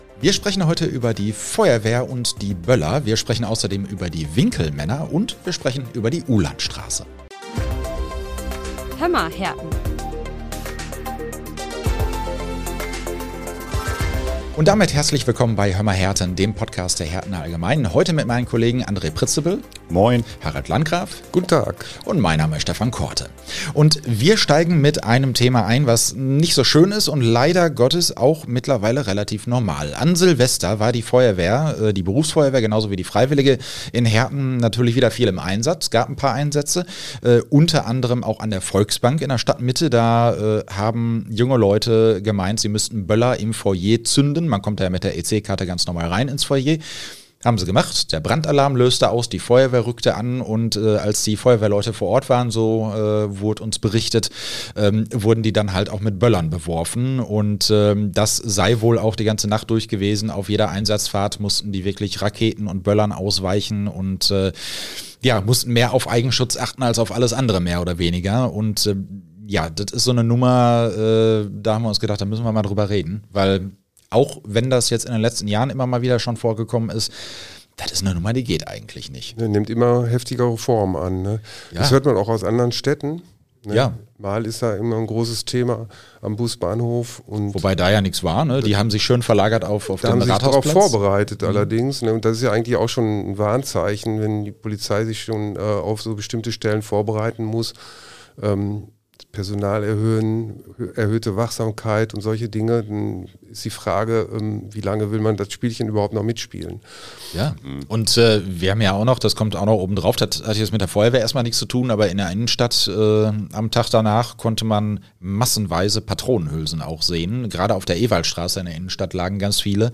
begrüßt im Studio seine Kollegen